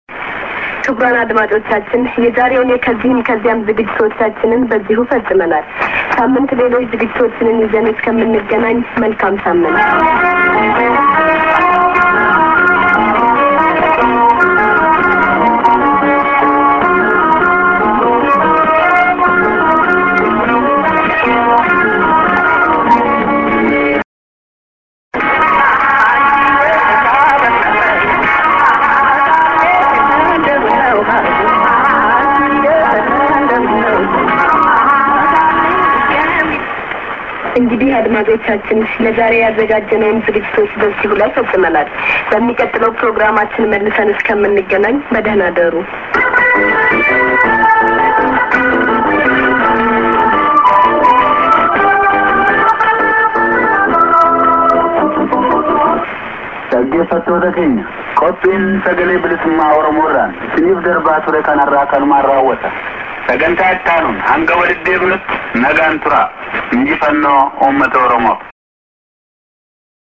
End ->music->ANN(women)->IS->ID(man)